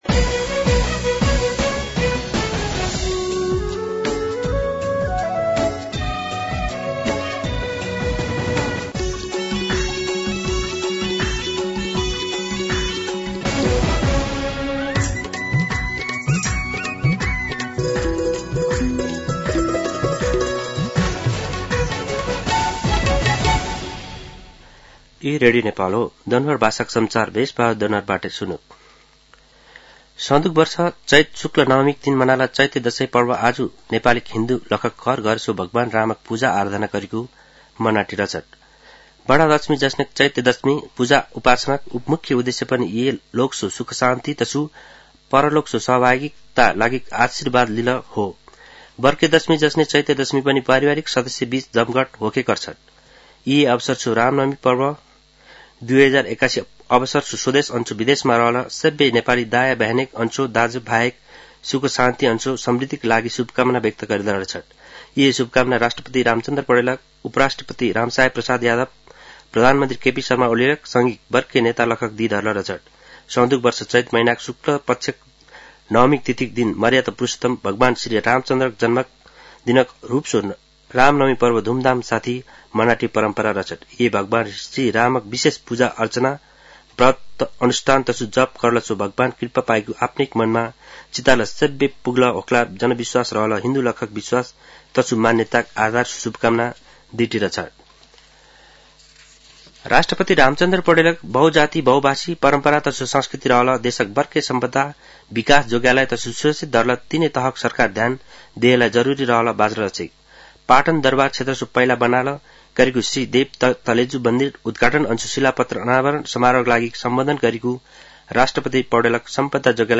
दनुवार भाषामा समाचार : २४ चैत , २०८१
Danuwar-News-3.mp3